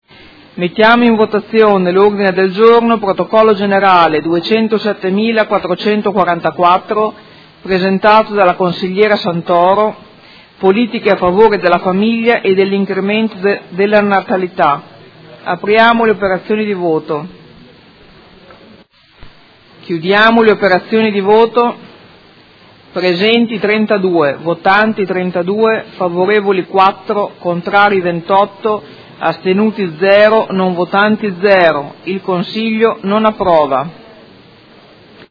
Presidente — Sito Audio Consiglio Comunale
Seduta del 20/12/2018. Mette ai voti Ordine del Giorno Prot. Gen. 207444